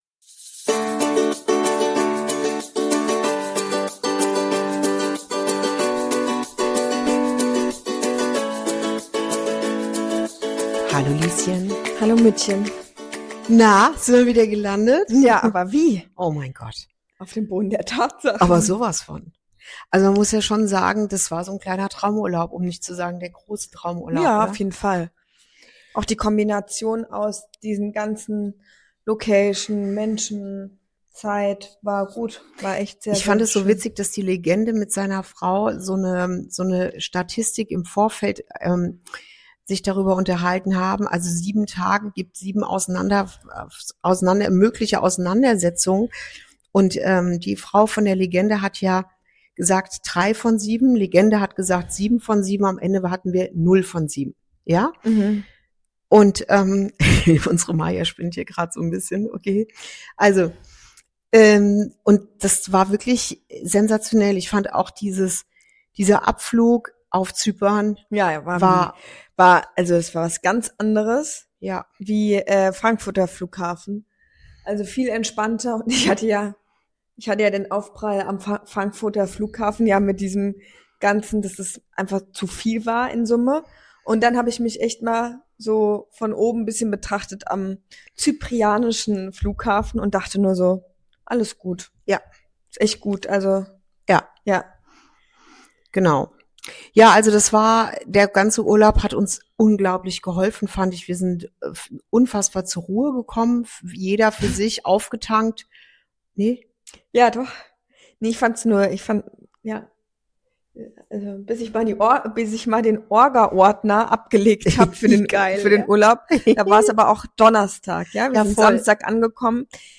Folge 8: Nach dem Urlaub ist vor dem Wandel – Zwischen Rückkehr, Rhythmus & echter Verbindung ~ Inside Out - Ein Gespräch zwischen Mutter und Tochter Podcast